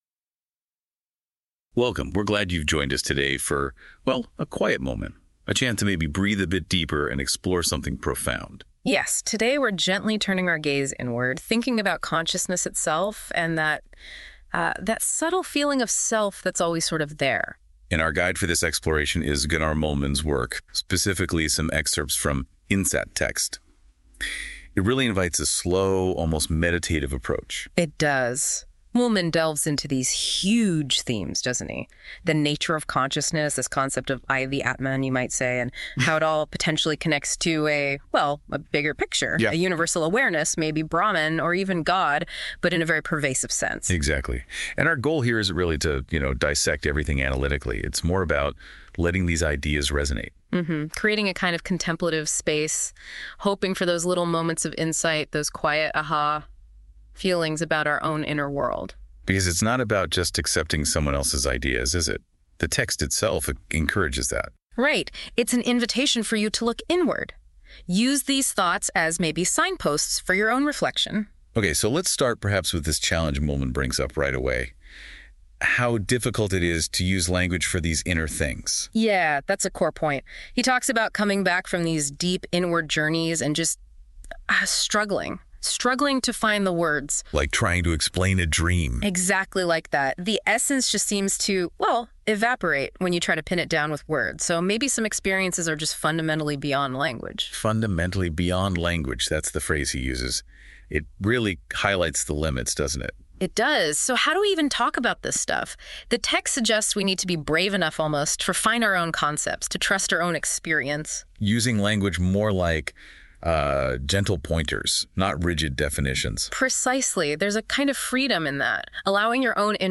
GET AN AI GENERATED SUMMARY OF THE CHAPTER IN PODCAST FORMAT